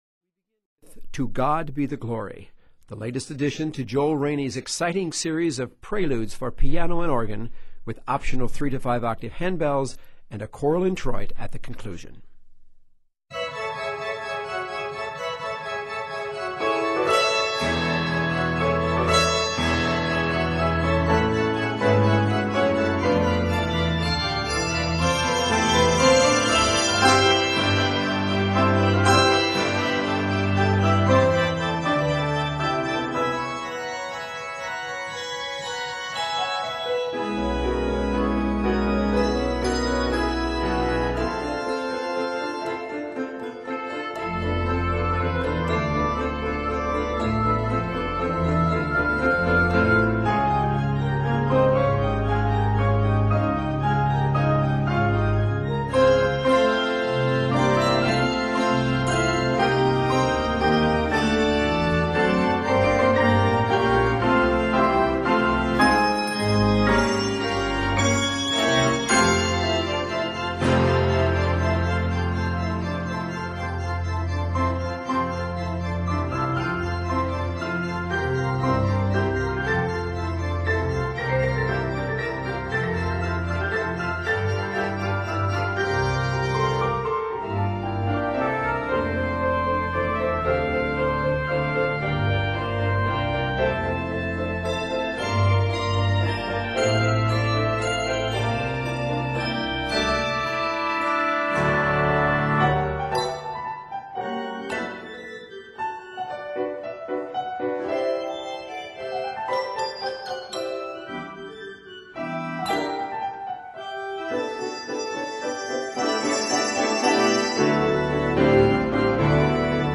festive worship opener